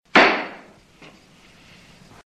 JUDGE GAVEL SINGLE.mp3
Original creative-commons licensed sounds for DJ's and music producers, recorded with high quality studio microphones.
judge_gavel_single_v7e.ogg